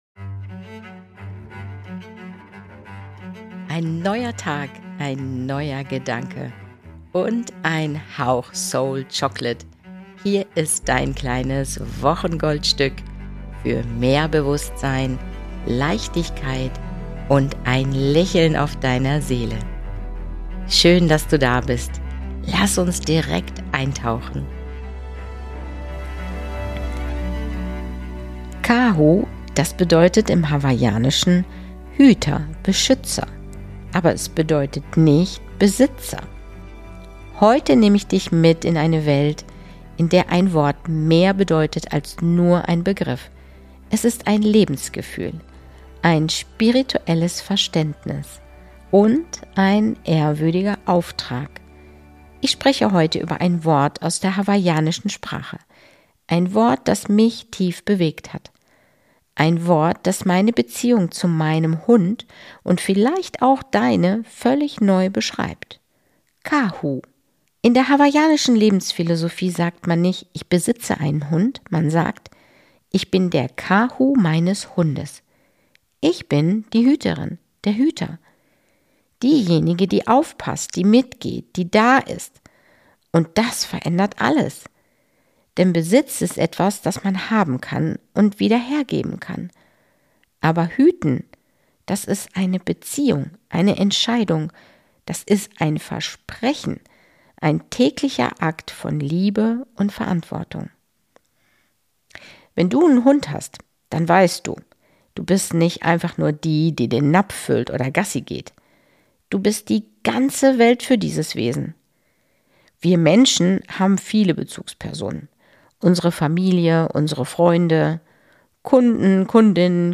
In dieser Folge nehme ich dich mit spazieren - mit meinem Hund.